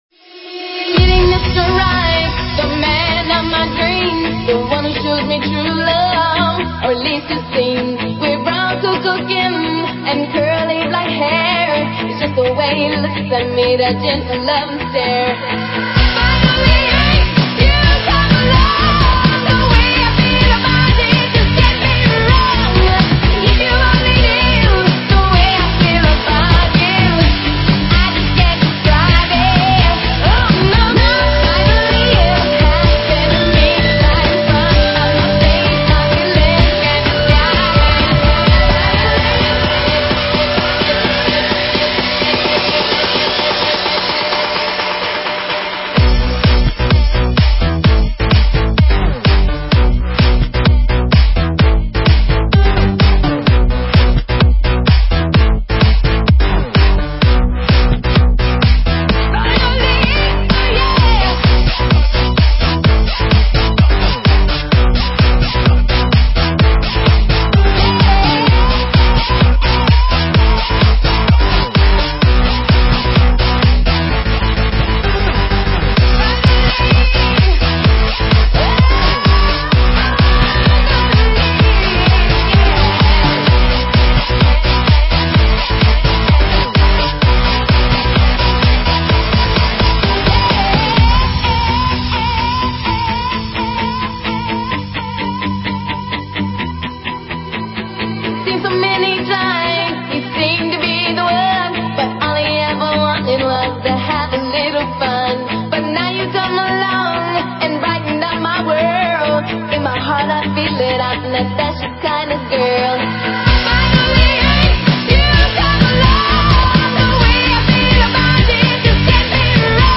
Стиль: Electro House